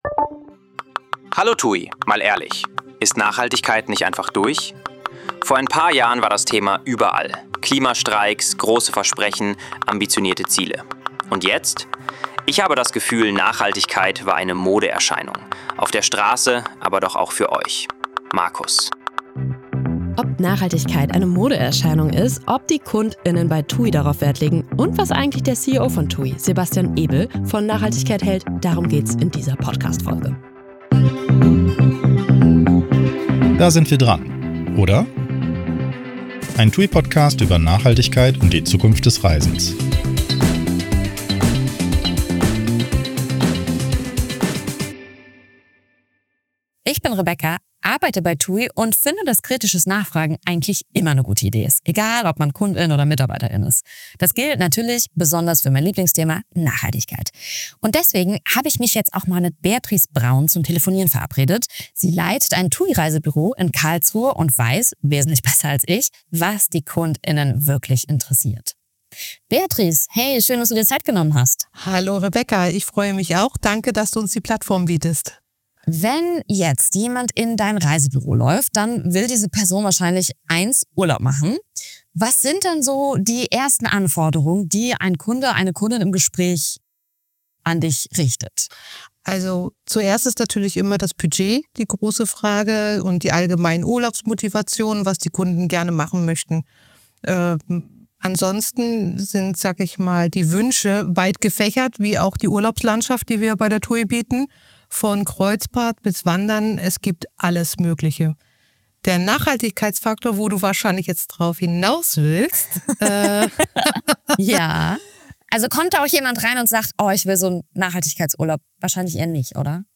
Ist Nachhaltigkeit im Tourismus nur ein Hype – oder gekommen, um zu bleiben? Wir sprechen mit einer TUI‑Reisebüroleiterin und TUI-CEO Sebastian Ebel über Kundenerwartungen, Wirtschaftlichkeit und warum nachhaltiges Handeln heute ein echter Wettbewerbsfaktor ist.